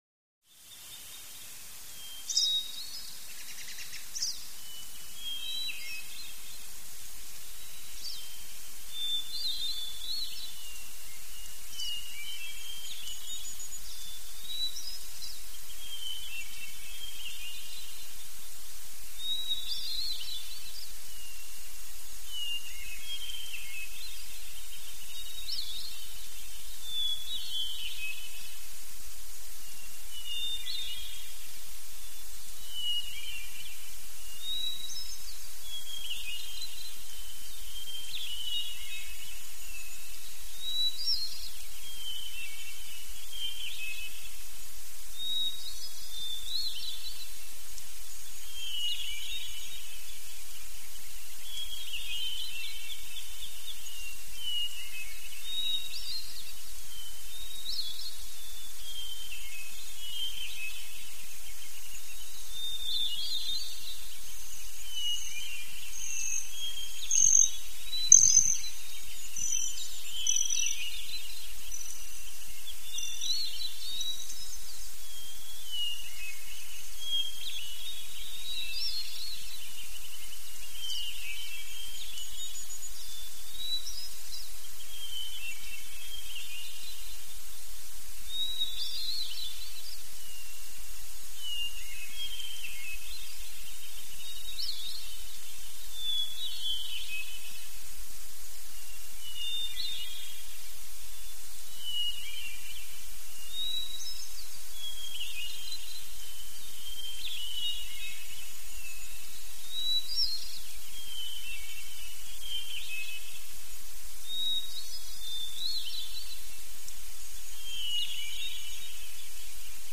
Mountains
Mountain Ambience With Medium Perspective Bird Song And Light Wind Rustling Leaves.